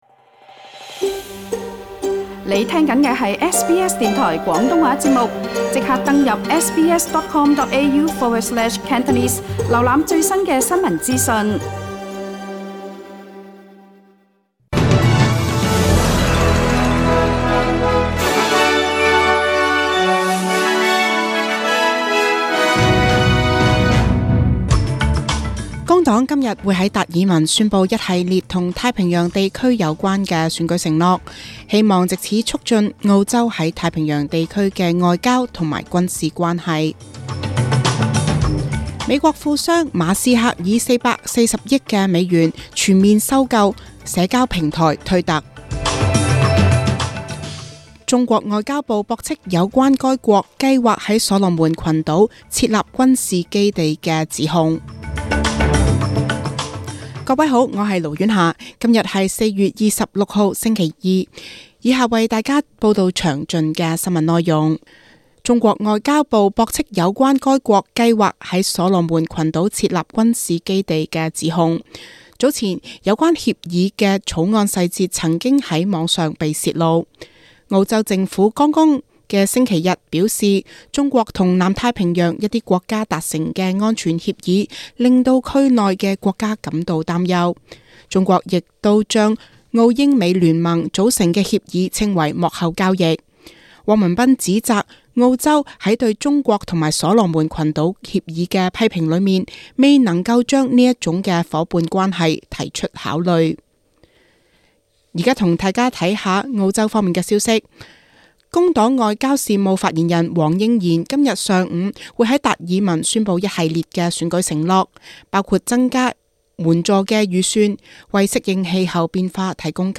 SBS 中文新聞（4月26日）